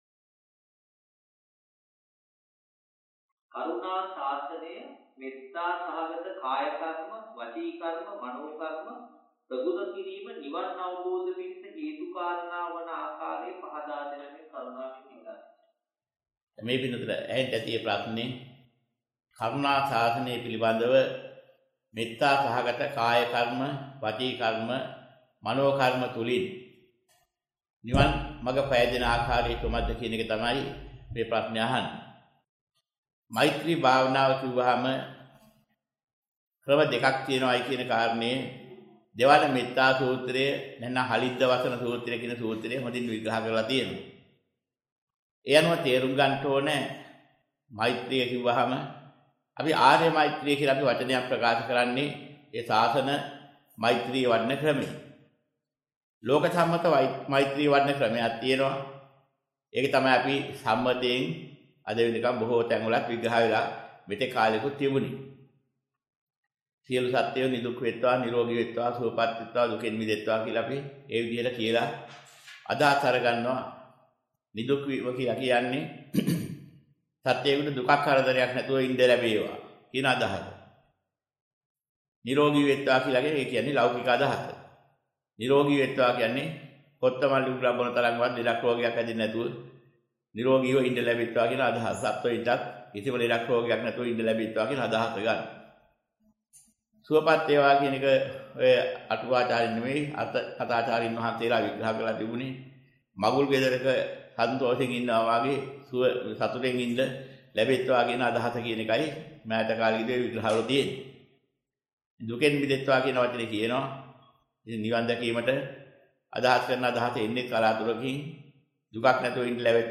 වෙනත් බ්‍රව්සරයක් භාවිතා කරන්නැයි යෝජනා කර සිටිමු 1:29:40 10 fast_rewind 10 fast_forward share බෙදාගන්න මෙම දේශනය පසුව සවන් දීමට අවැසි නම් මෙතැනින් බාගත කරන්න  (51 MB)